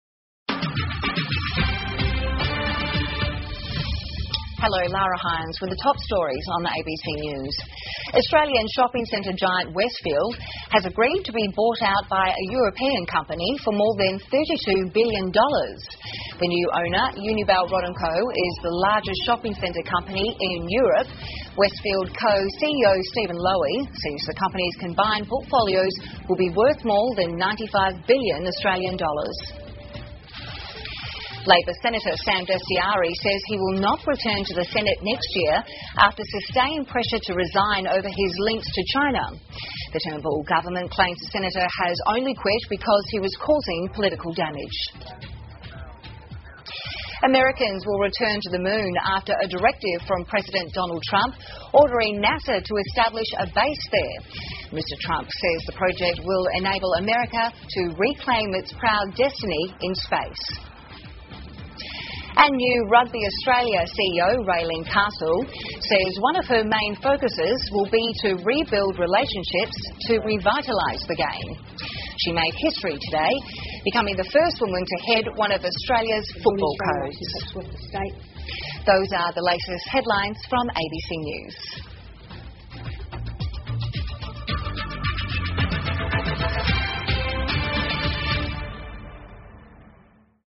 澳洲新闻 (ABC新闻快递) 澳大利亚议员被迫辞职 特朗普宣布美国宇航员将重返月球 听力文件下载—在线英语听力室